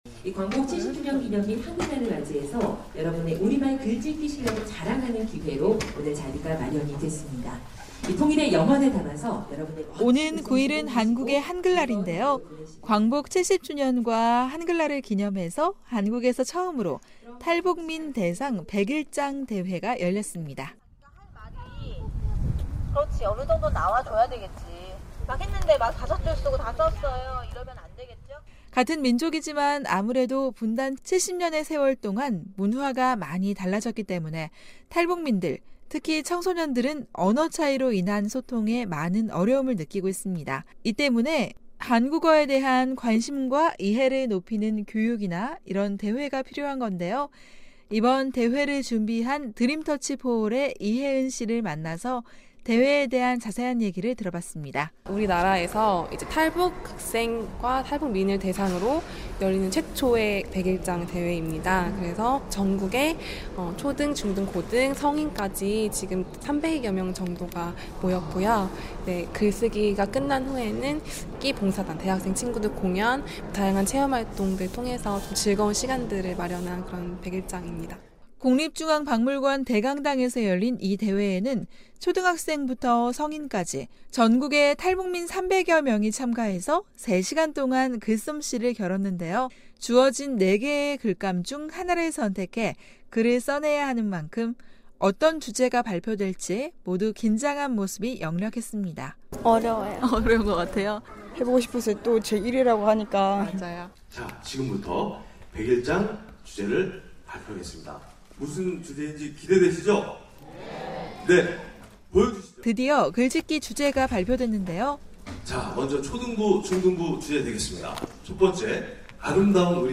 그 동안 한국과 북한에서 사용하는 말도 많이 달라졌는데요, 한국말에 대한 탈북민들의 관심과 이해를 높여 원활한 정착을 돕기 위해 한국에서 처음으로 탈북민을 대상으로 하는 백일장 대회가 열렸습니다. 서울에서